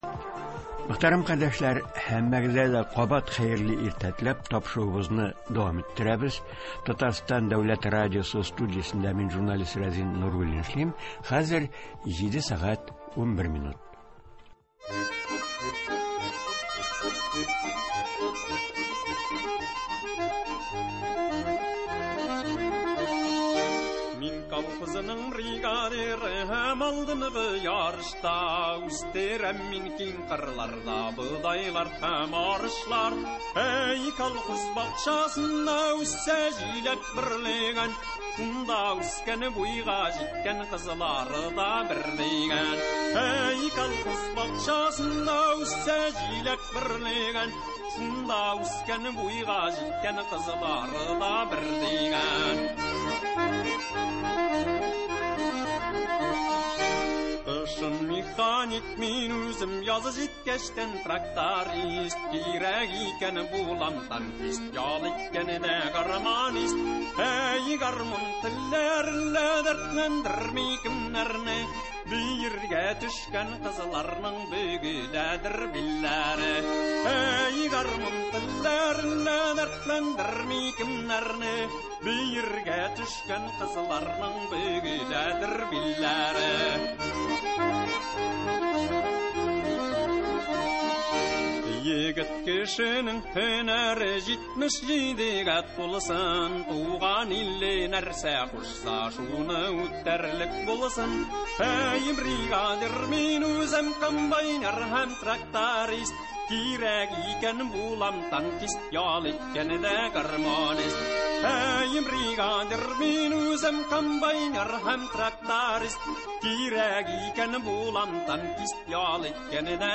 Бу эшләр республикабызда ничек бара? Быелгы рекордлы уңыш нәтиҗәсендә техника паркын яңарту мөмкинлеге арттымы? Татарстан авыл хуҗалыгы һәм азык-төлек министры урынбасары Тәлгать Таһирҗанов турыдан-туры эфирда шулар хакында сөйләячәк һәм тыңлаучылар сорауларына җавап бирәчәк.